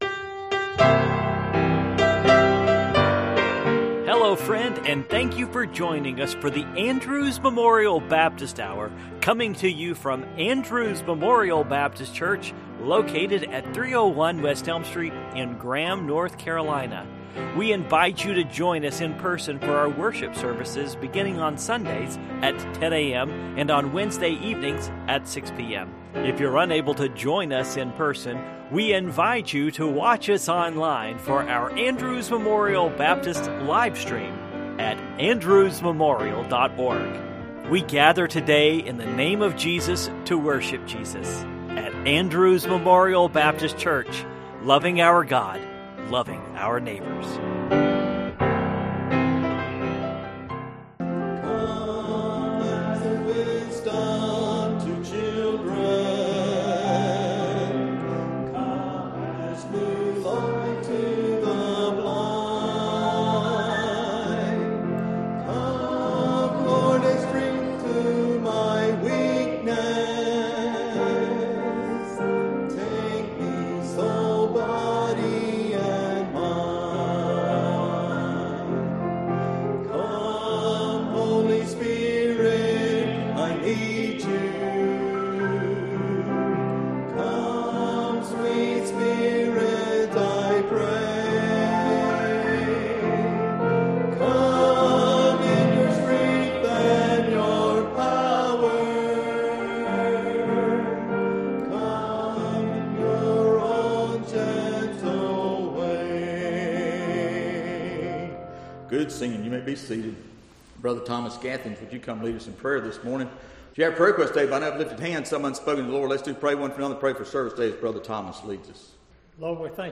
Passage: Proverbs 23:22-26 Service Type: Morning Worship